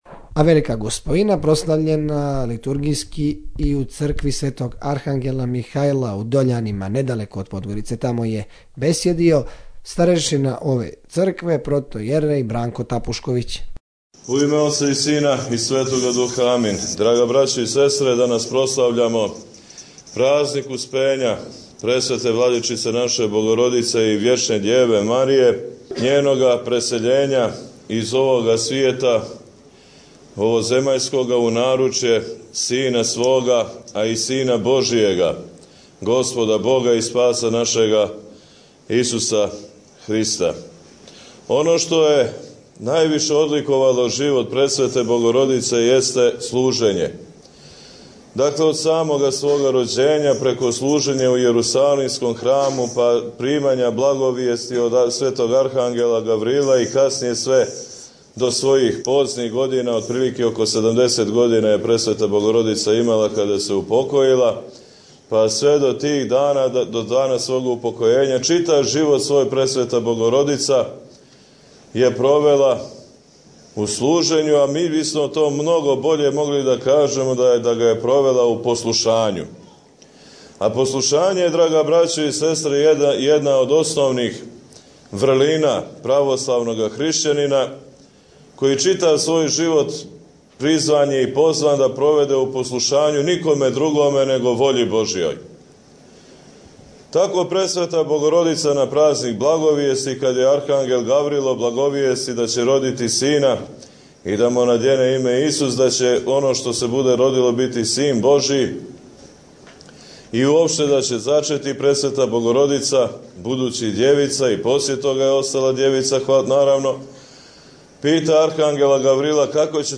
Бесједе Наслов